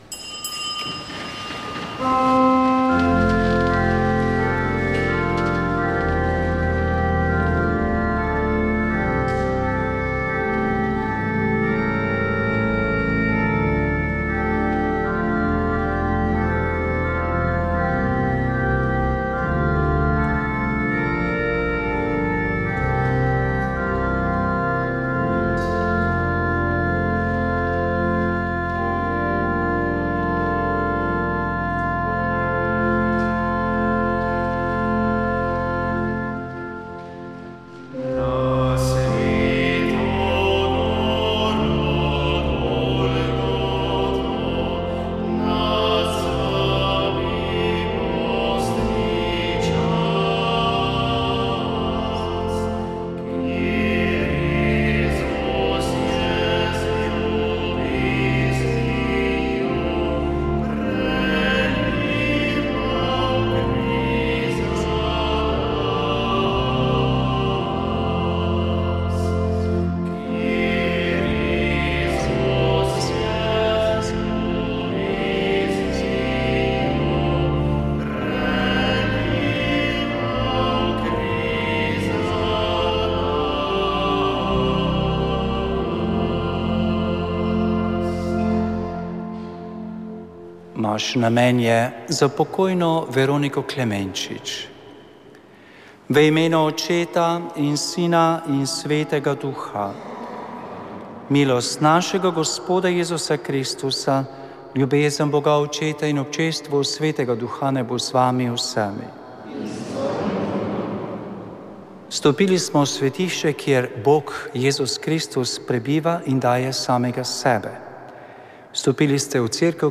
Sveta maša
Sv. maša iz cerkve sv. Marka na Markovcu v Kopru 8. 1.
ljudsko petje